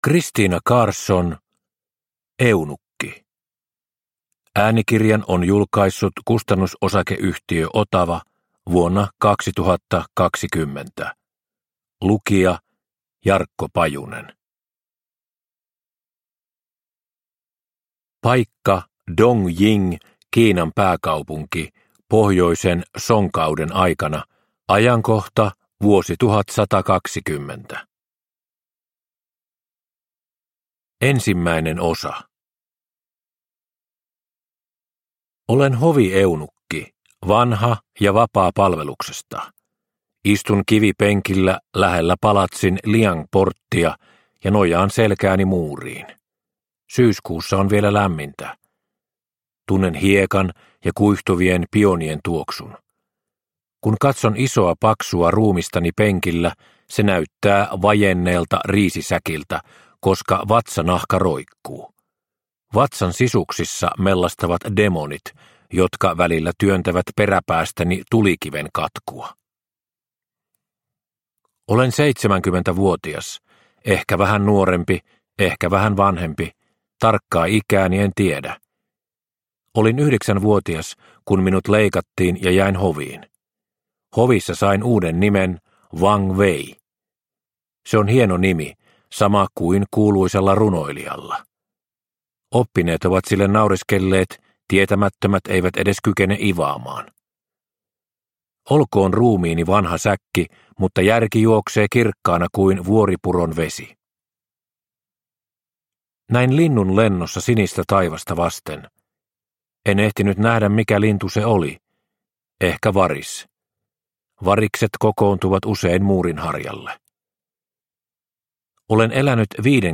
Eunukki – Ljudbok – Laddas ner